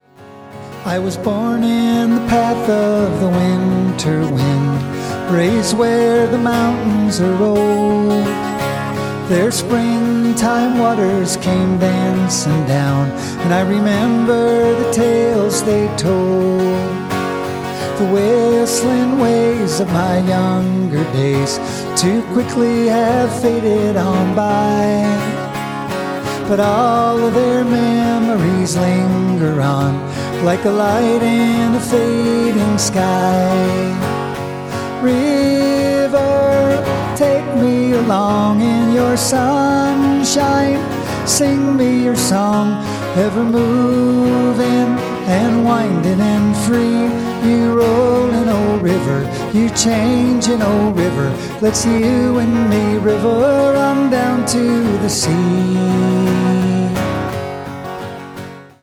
I decided to record these songs the way they were written.